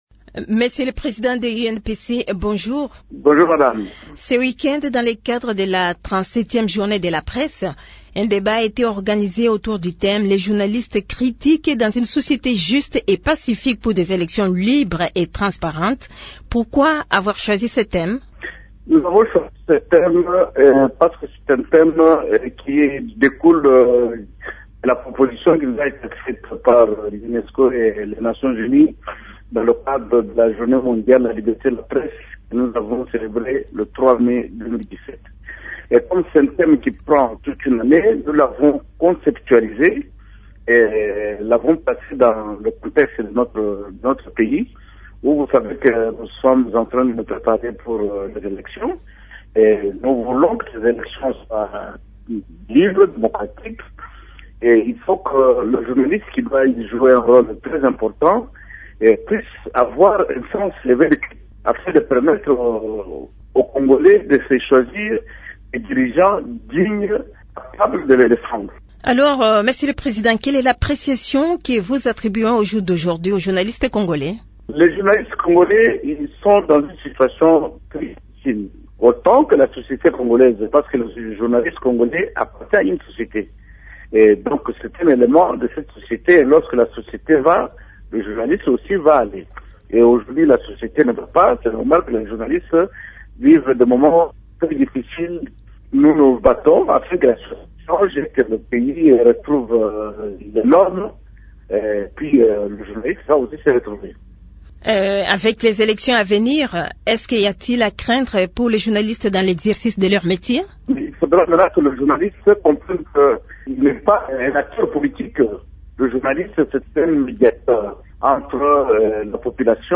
Invité de Radio Okapi ce mardi